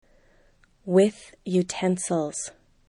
(with) utensils  jutɛnsəlz